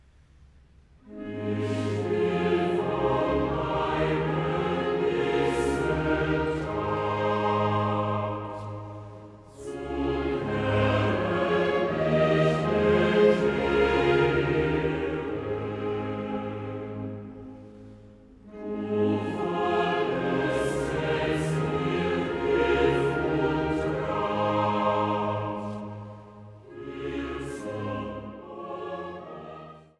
Passionskantate für Soli, Chor und Orchester
Tutti „Unsre Seele ist gebeuget zu der Erden“